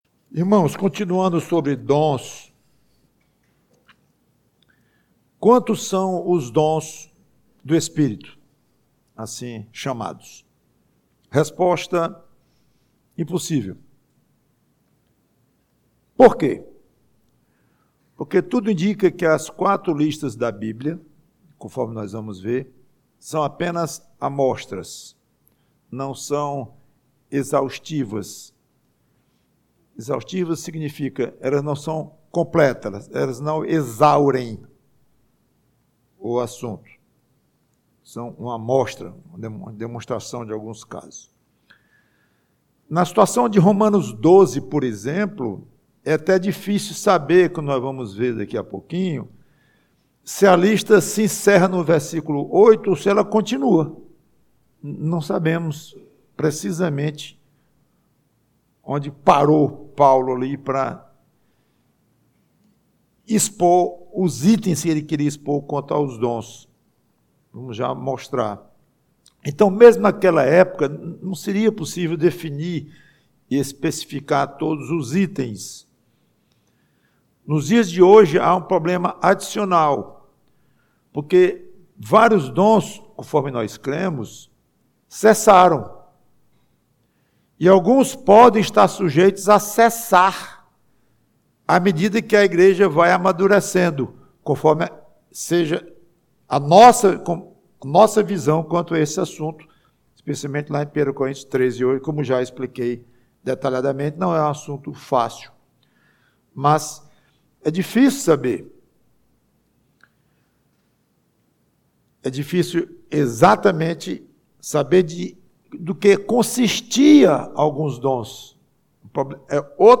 PREGAÇÃO Quantos são os dons?